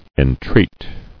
[en·treat]